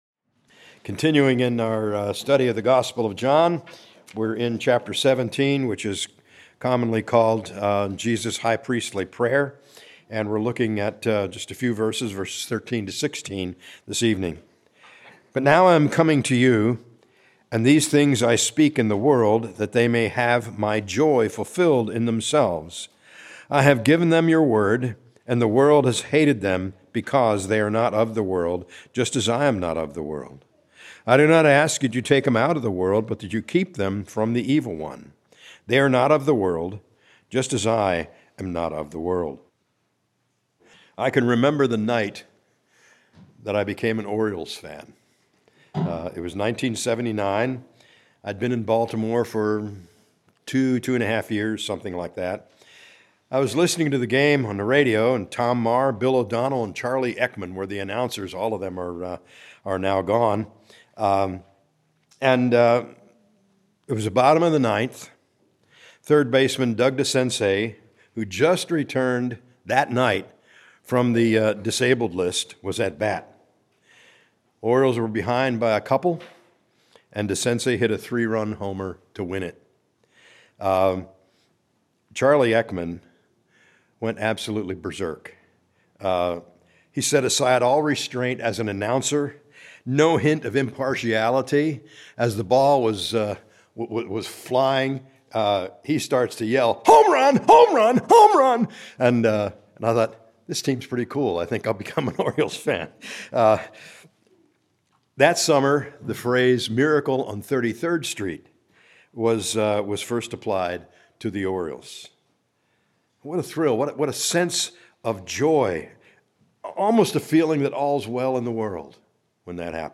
Sunday Worship 11 AM & 6 PM